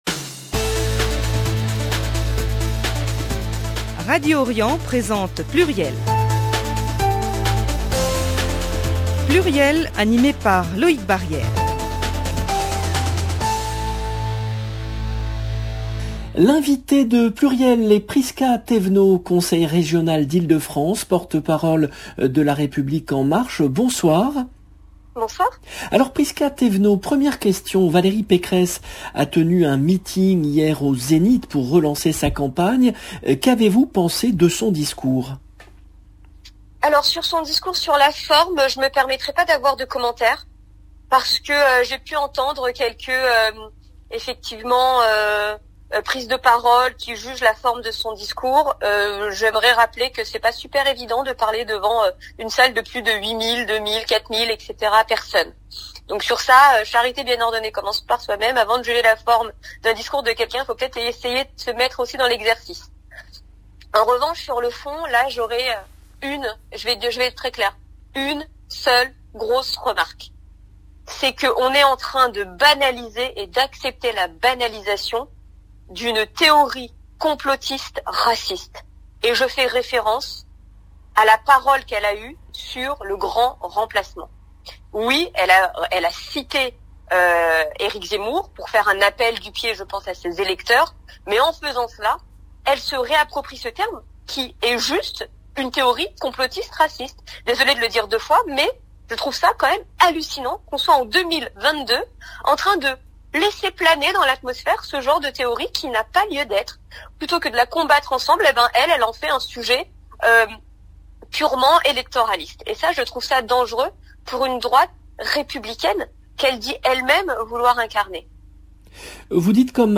L’invitée de PLURIEL est Prisca Thévenot , conseillère régionale d’Ile-de-France, porte-parole de LREM